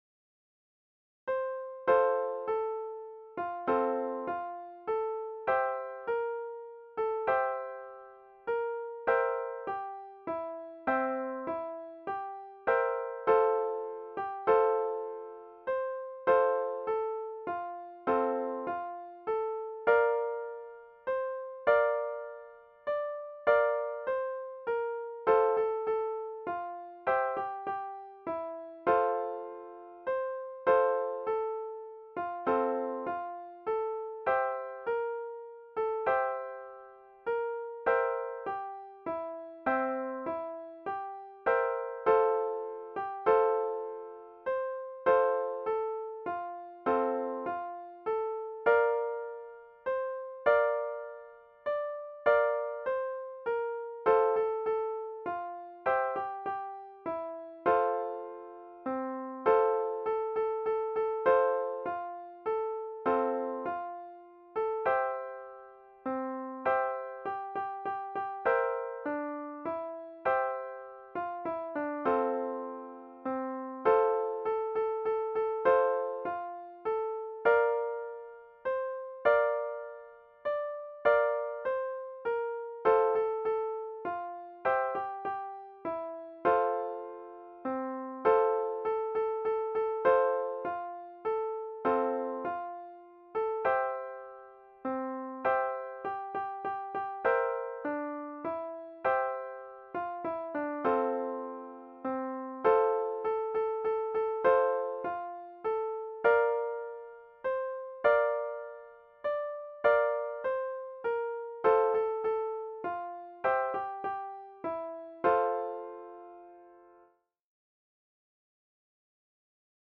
Deense volksmuziek